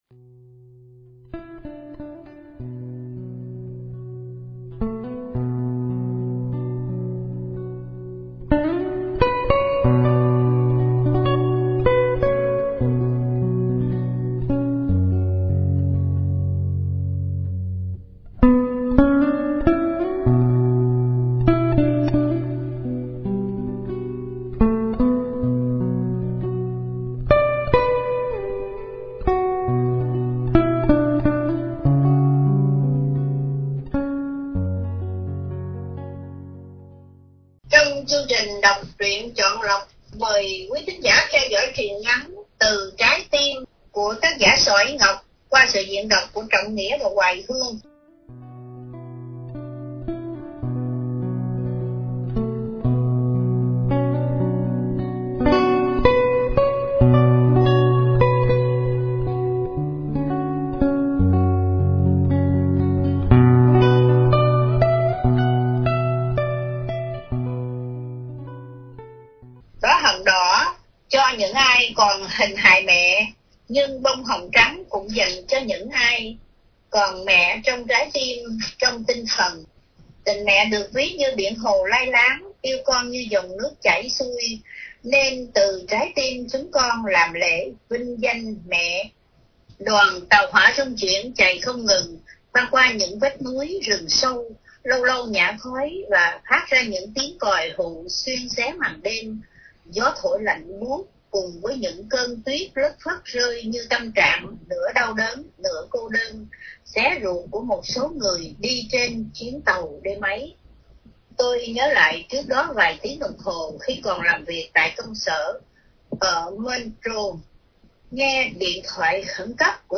Đọc Truyện Chọn Lọc – Truyện Ngắn – Từ Trái Tim